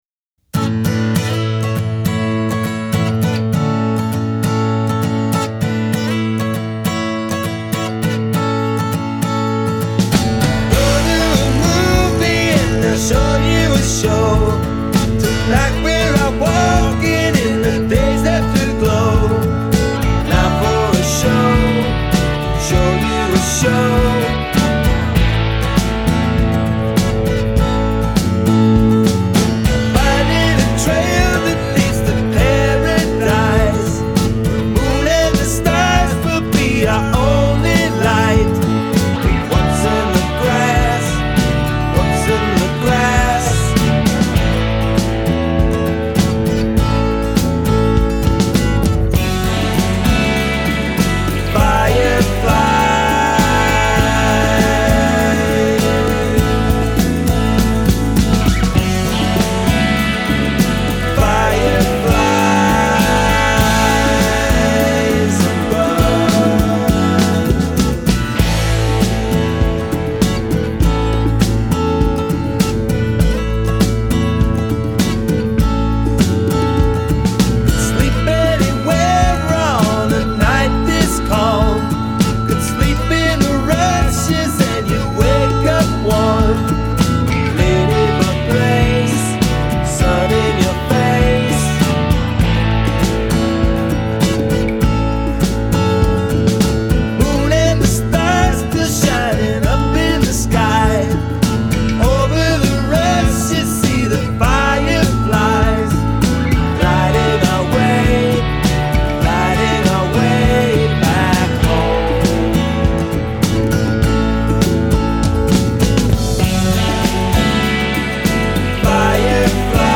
vocals and guitars + percussion, keyboards & harmonicas
drums + percussion and backup vocals
bass and vocals + keyboards and percussion